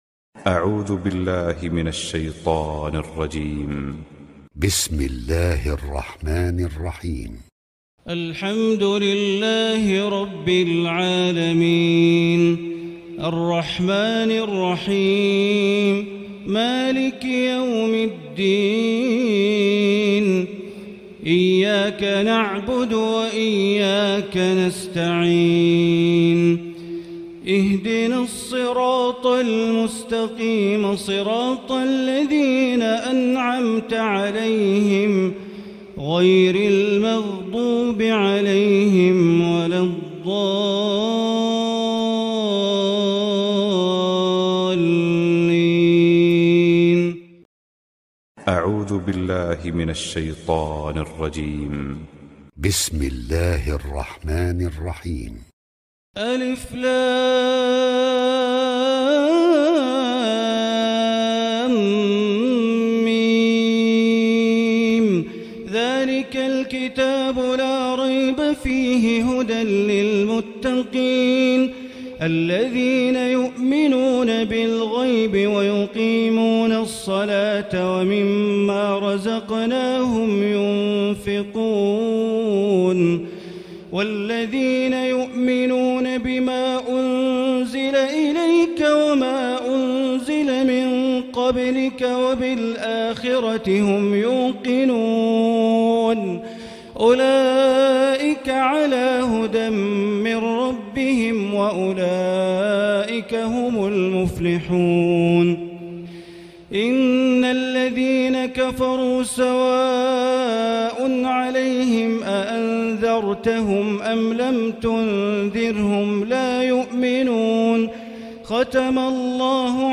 تراويح الليلة الأولى رمضان 1439هـ من سورة البقرة (1-91) Taraweeh 1st night Ramadan 1439H from Surah Al-Baqara > تراويح الحرم المكي عام 1439 🕋 > التراويح - تلاوات الحرمين